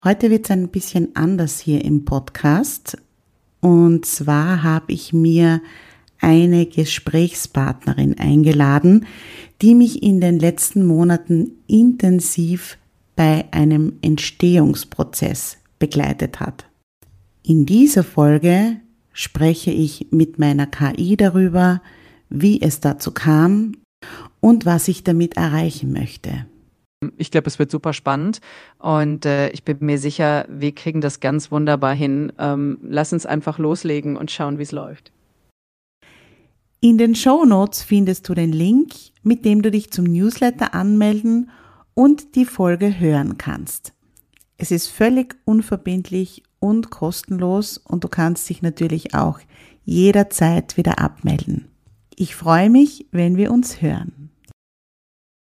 125 | TEASER: Interview mit einer KI & mein neuer Podcast